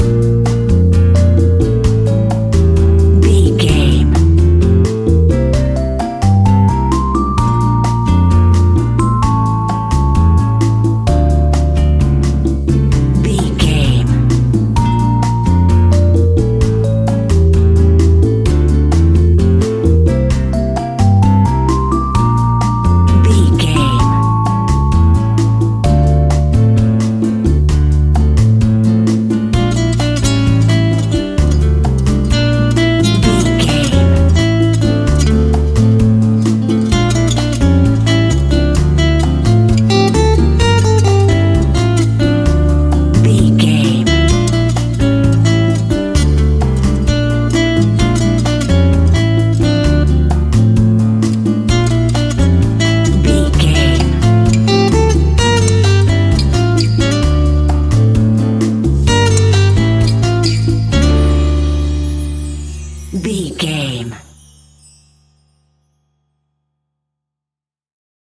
An exotic and colorful piece of Espanic and Latin music.
Aeolian/Minor
flamenco
maracas
percussion spanish guitar
latin guitar